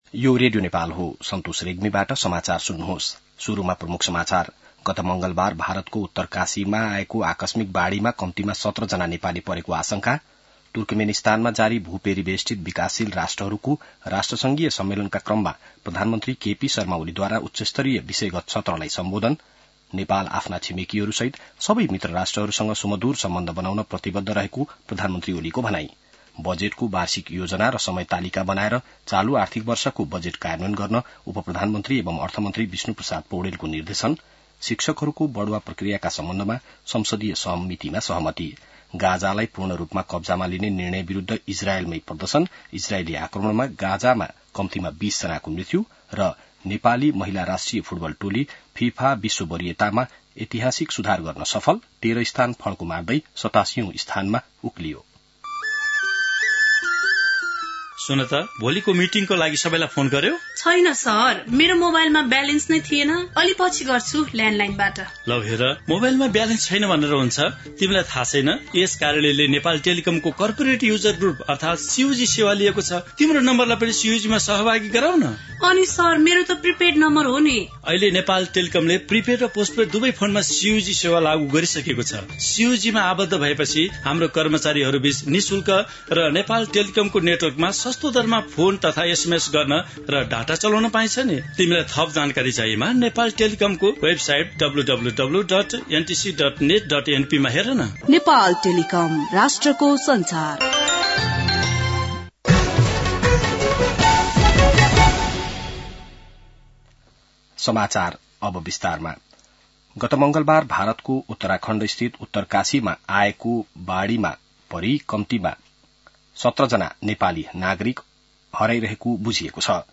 बिहान ७ बजेको नेपाली समाचार : २३ साउन , २०८२